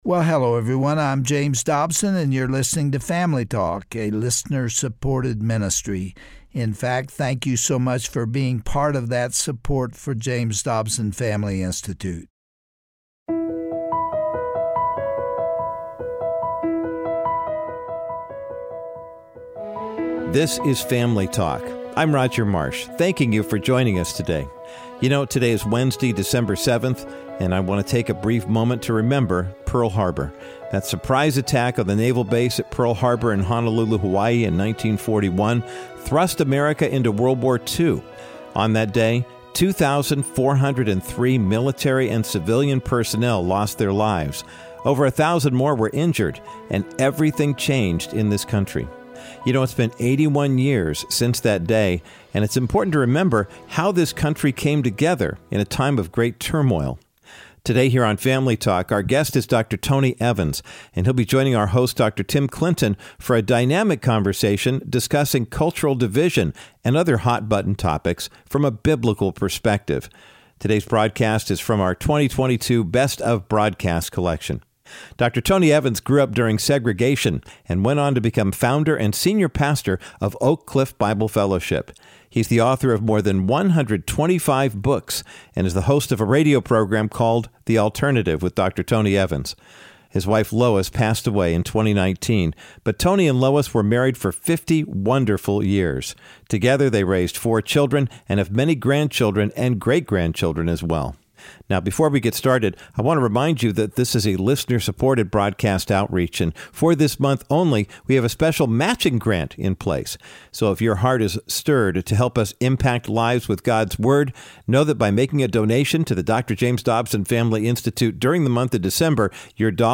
On today’s edition of Family Talk, Dr. Tony Evans, senior pastor of Oak Cliff Bible Fellowship, proclaims that God is not colorblind.